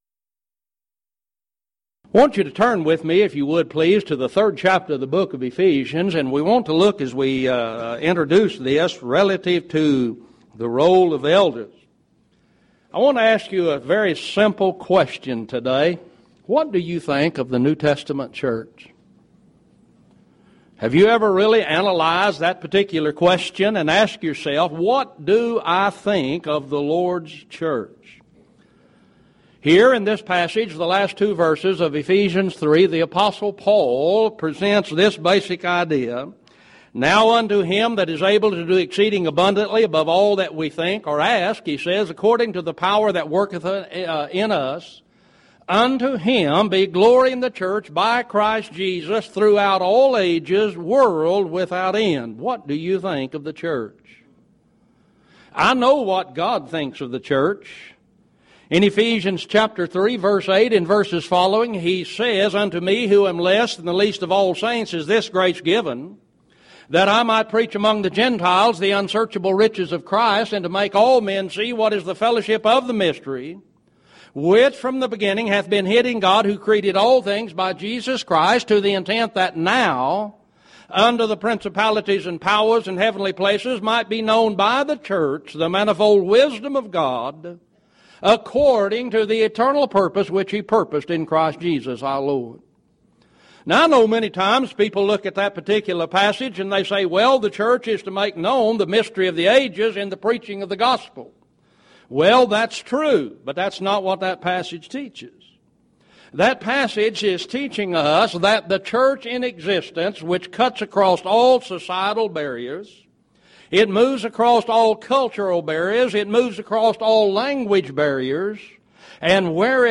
Event: 2006 Speaking as the Oracles of God Lectures